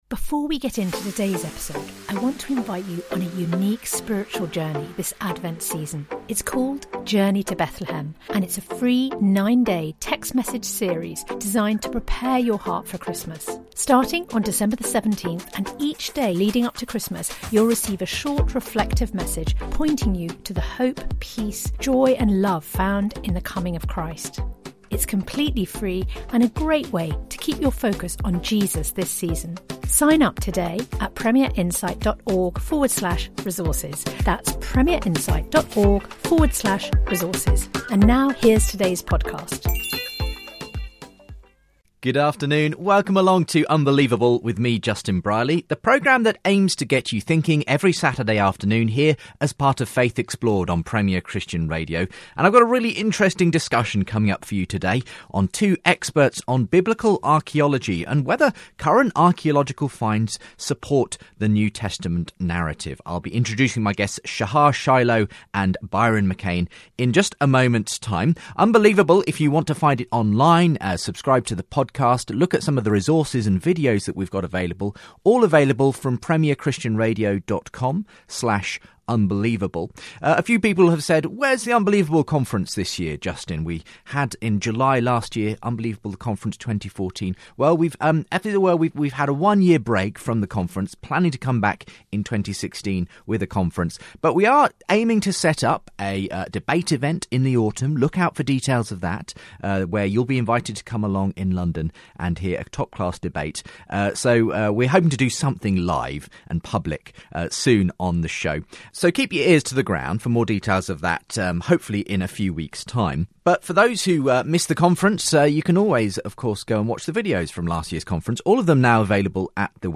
Christianity, Religion & Spirituality 4.6 • 2.3K Ratings 🗓 12 June 2015 ⏱ 79 minutes 🔗 Recording | iTunes | RSS 🧾 Download transcript Summary A conversation on whether modern archaeology support the new Testament accounts.